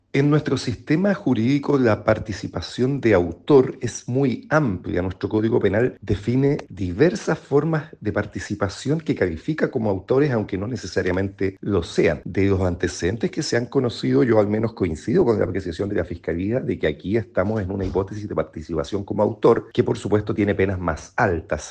El exfiscal de Alta Complejidad, Carlos Gajardo, señaló que -a la luz de los antecedentes- la diputada Pérez podría ser formalizada en calidad de autora, como lo propone la Fiscalía Regional de Antofagasta.